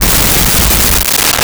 Explosion2
explosion2.wav